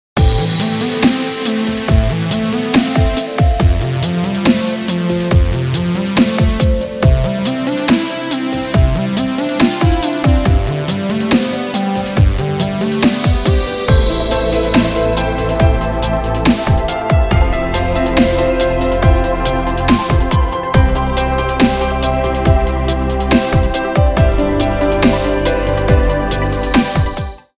رینگتون پر انرژی و بی کلام
برداشتی آزاد از موسیقی های بی کلام خارجی